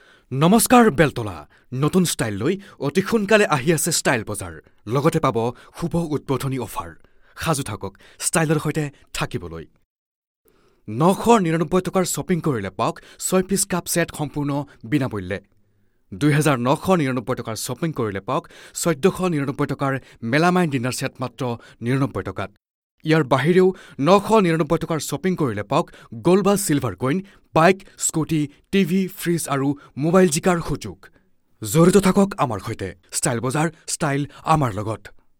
Professional Assamese Voice Artiste and Translator working since 10 years. ....